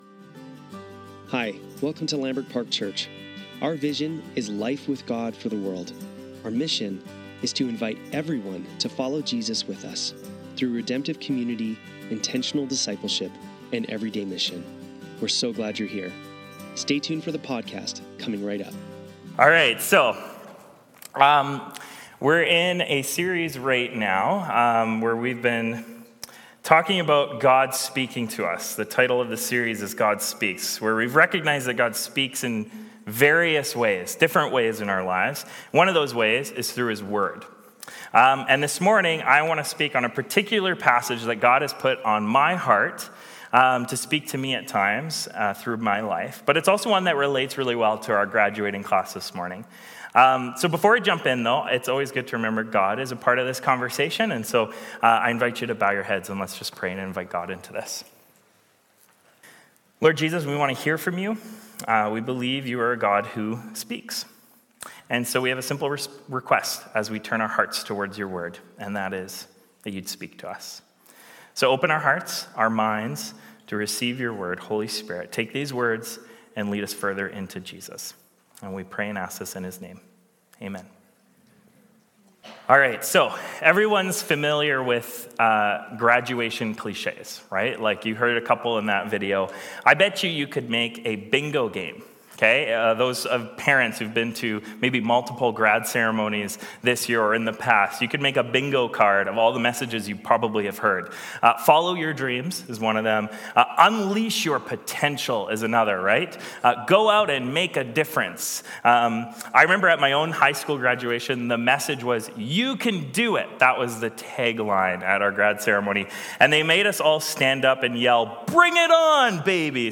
Lambrick Sermons | Lambrick Park Church